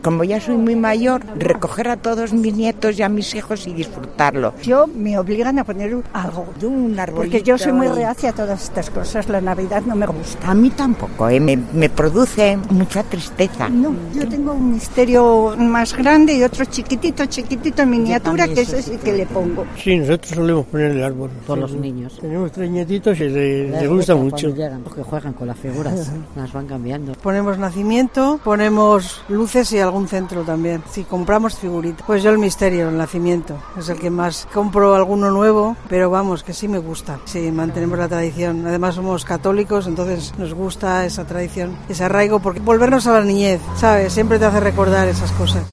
Los salmantinos afirman en declaraciones a Cope, que también decoran sus casas con el árbol o el Nacimiento